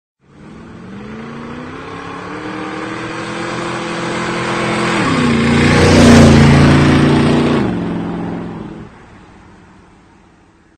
звук двигателя
мотор
Звук Классного байка